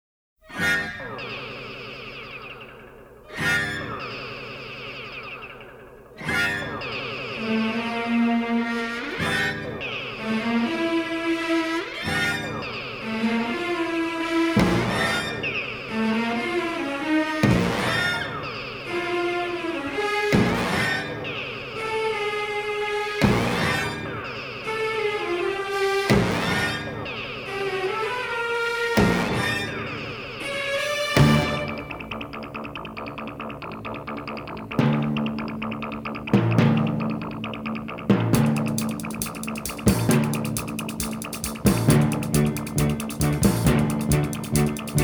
acoustic guitar
remixed and mastered from the original 1/2" stereo tapes.